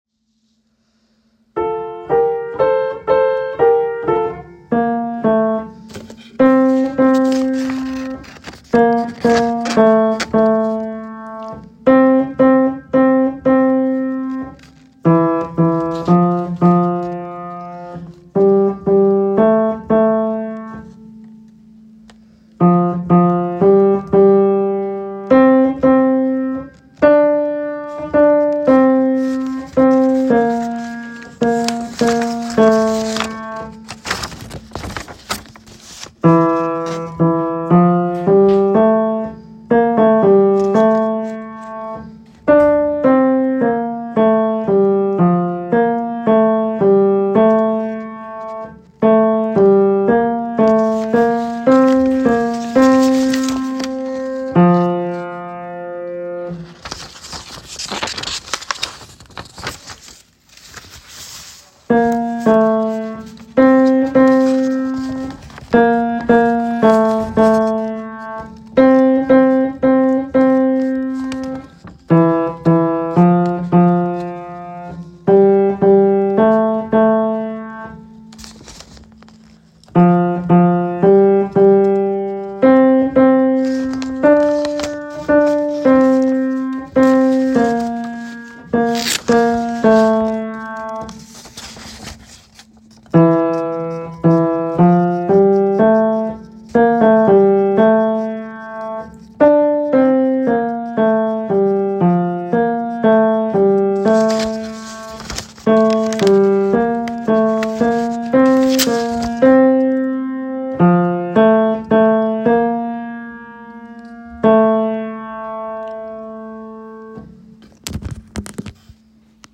Dona Nobis Pacem Tenor and Bass.m4a